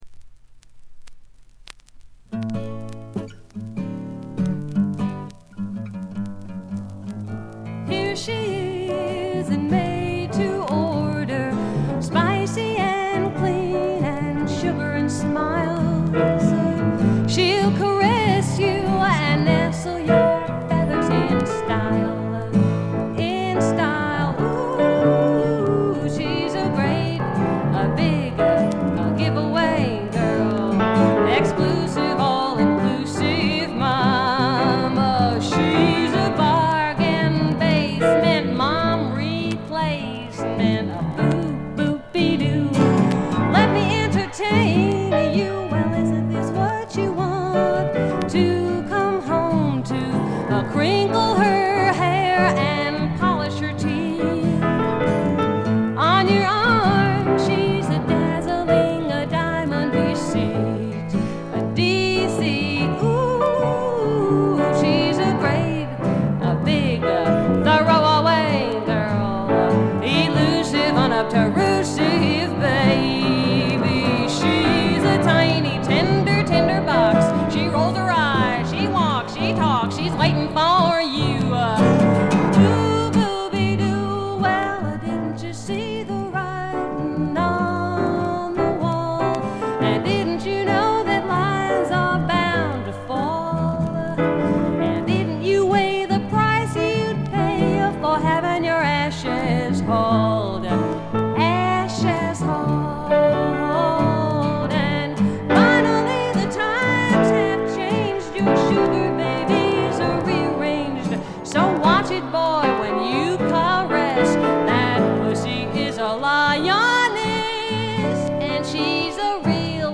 細かなバックグラウンドノイズやチリプチは多め大きめに出ますが鑑賞を妨げるほどではないと思います。
自主フォーク、サイケ・フォーク界隈でも評価の高い傑作です。
試聴曲は現品からの取り込み音源です。